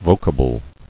[vo·ca·ble]